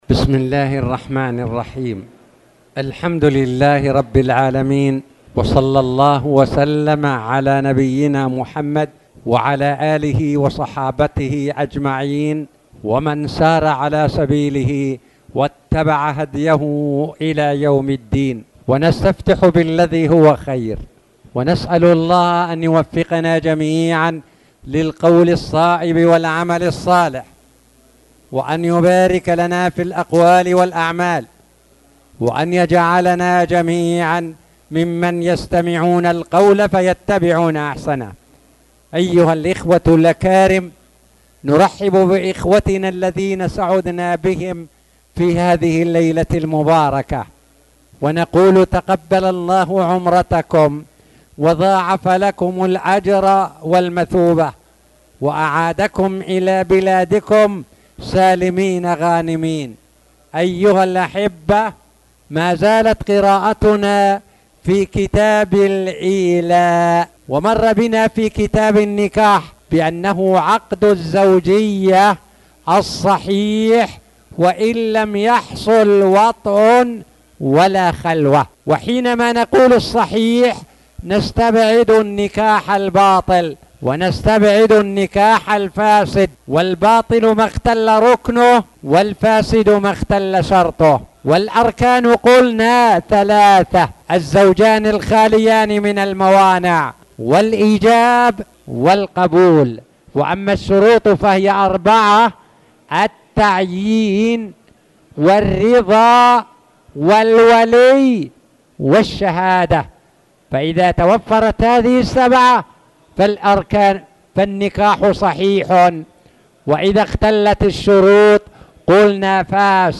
تاريخ النشر ٧ شعبان ١٤٣٨ هـ المكان: المسجد الحرام الشيخ